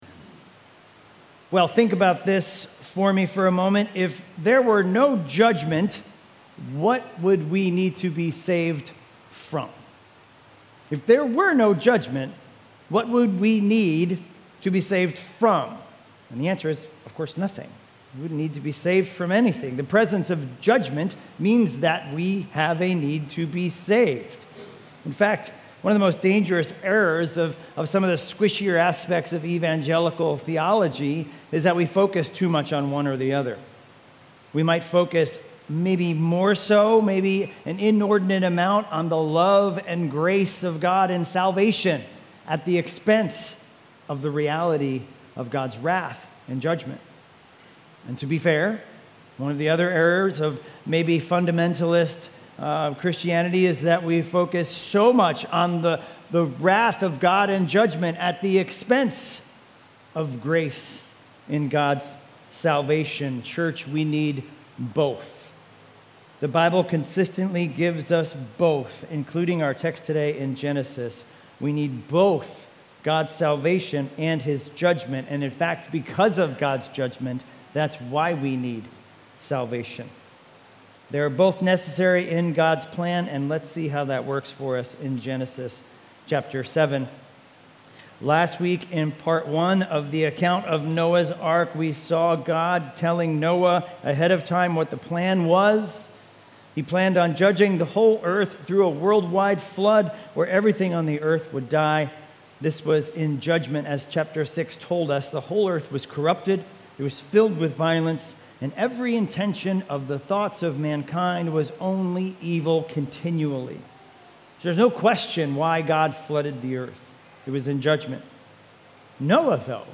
Weekly Sunday AM sermon series in Genesis.